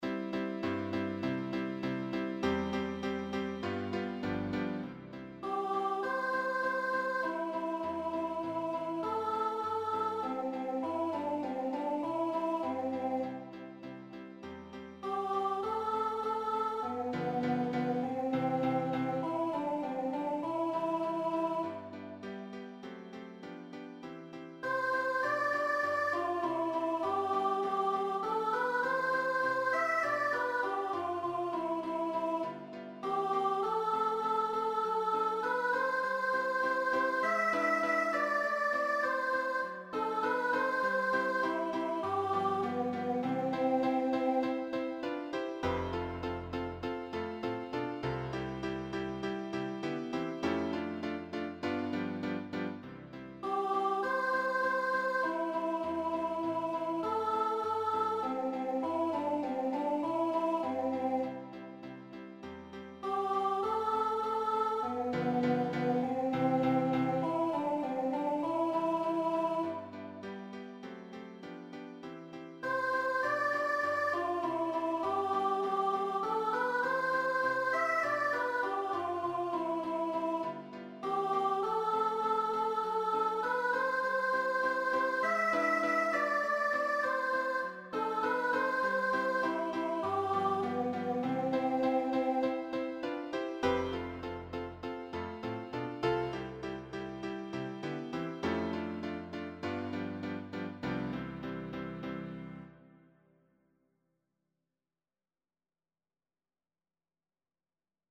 Number of voices: 1v Voicing: Solo medium Genre: Secular, Lied
Language: German Instruments: Piano